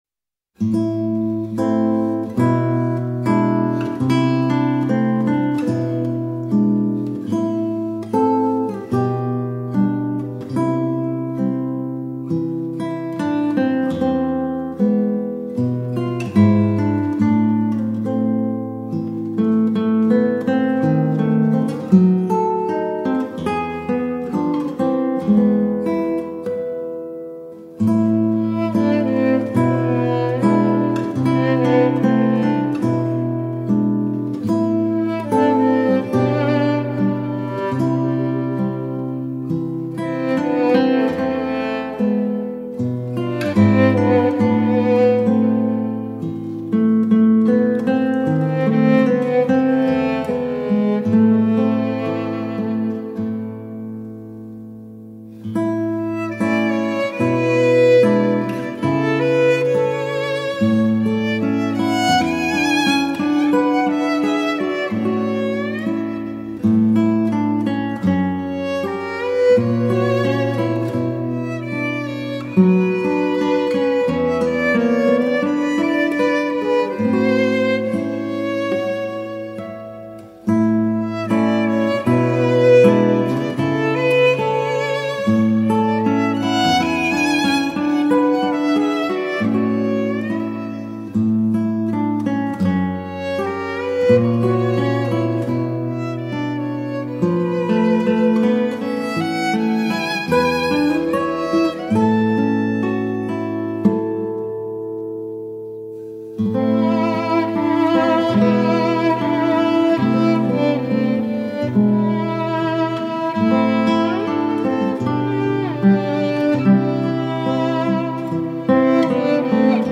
gitaar